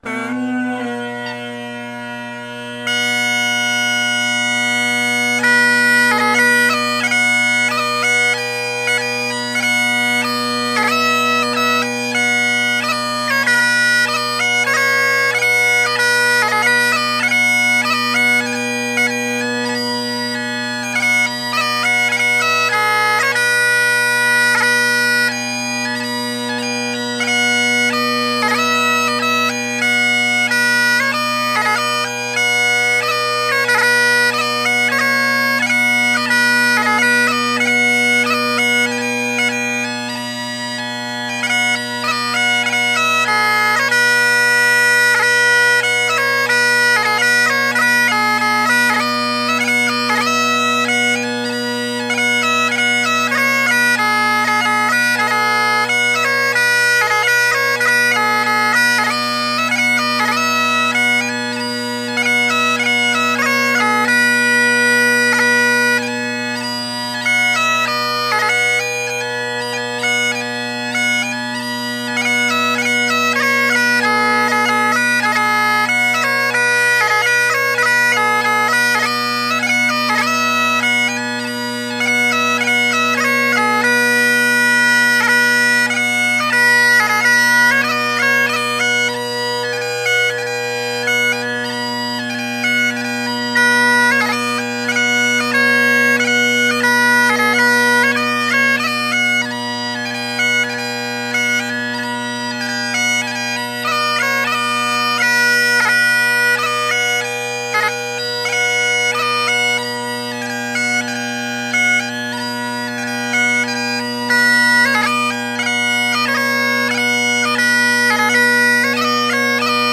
Inveran Chanter + new drone reed combo
Great Highland Bagpipe Solo
The whole top hand is sharp relative to high A. I thought, dang.
Note that the little chirps that occur on high G grace notes to/on low G are a result of the easy strength of the reed.
More stable than my previous setup.
The kids were asleep so this was recorded not in the bedroom, as usual, but in our walk-in closet, hence the bigger chanter sound relative to the drones compared to the recordings directly below.